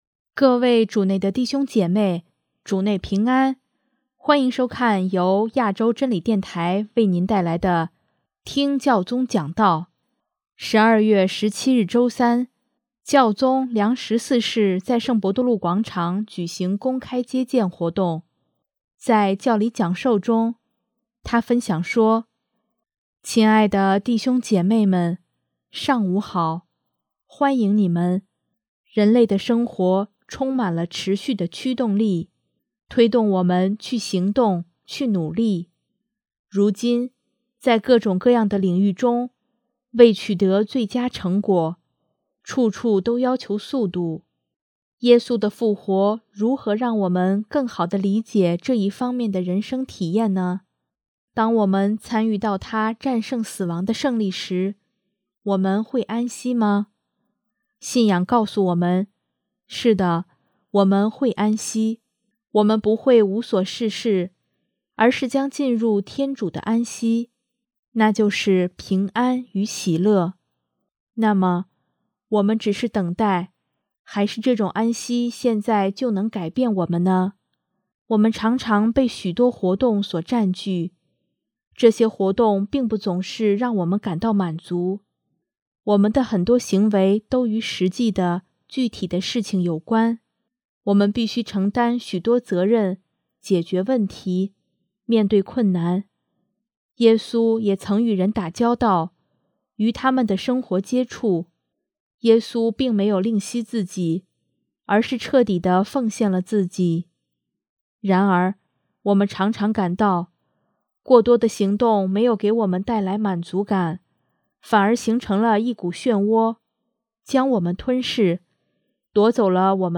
12月17日周三，教宗良十四世在圣伯多禄广场举行公开接见活动。